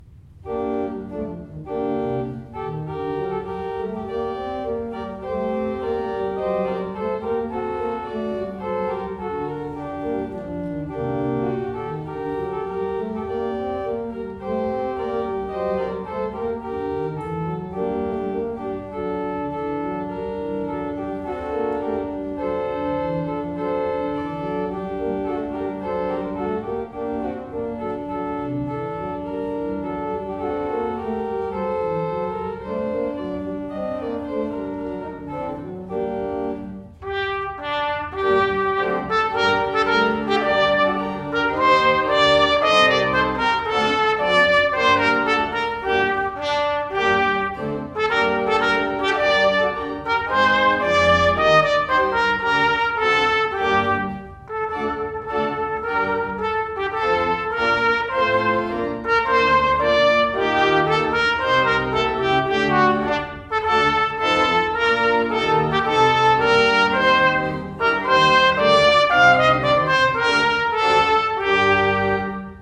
concert de Noël – église Ste Croix de Lorry
La marche des rois (chant populaire provençal, repris par Bizet dans L’Arlésienne)  –  Trompette et Orgue